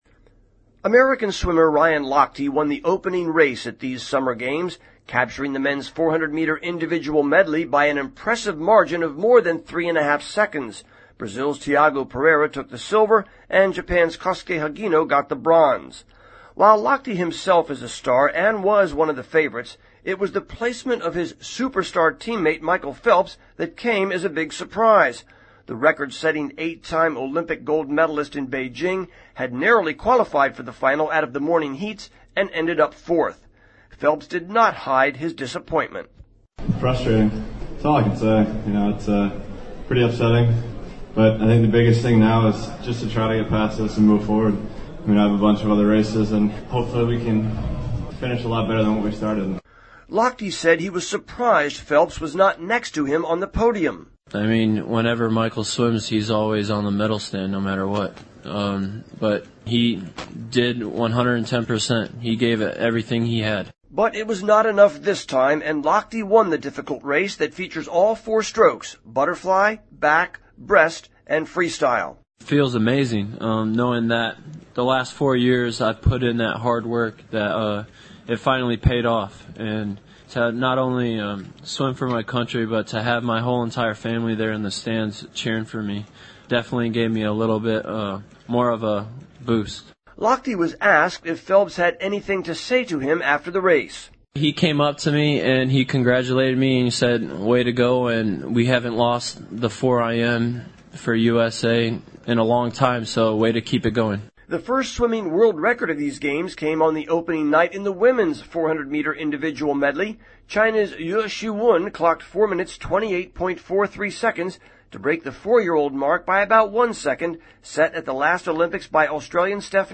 report from London